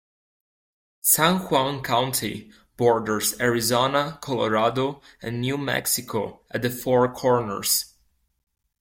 Pronounced as (IPA) /ʍɑːn/